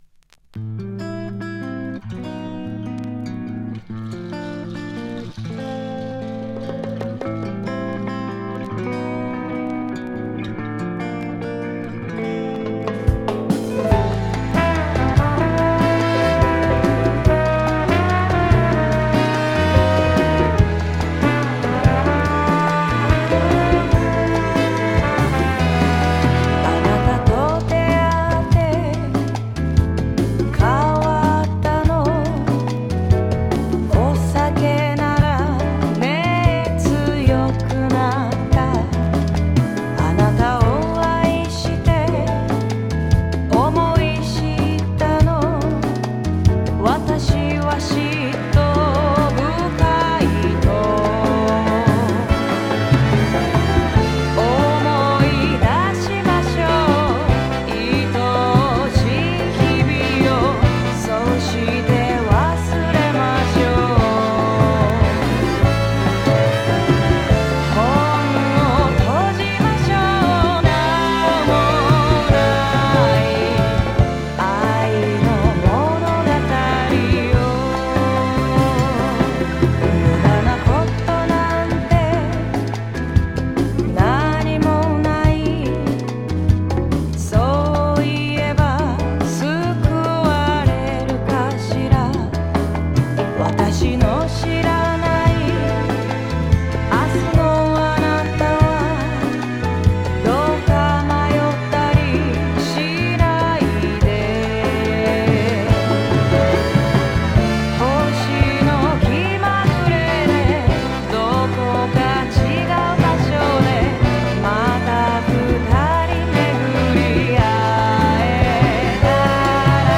哀愁歌謡ジャズを2曲収録したシングル盤。